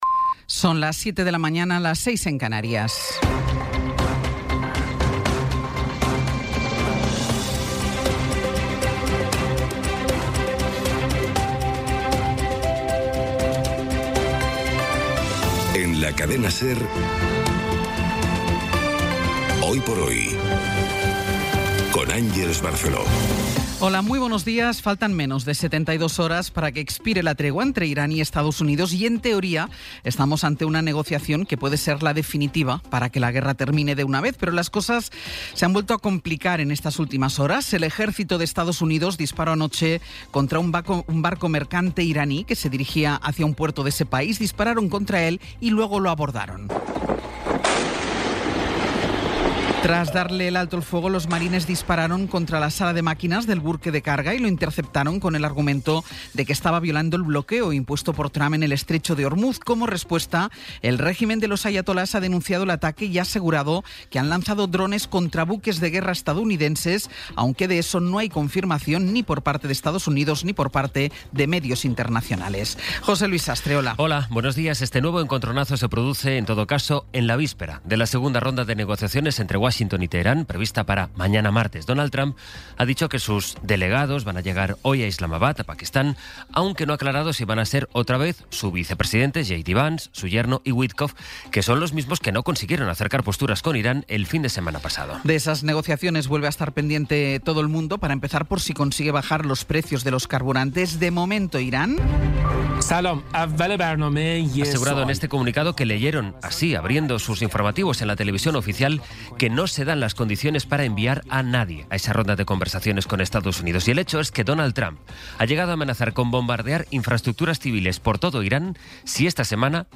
Resumen informativo con las noticias más destacadas del 20 de abril de 2026 a las siete de la mañana.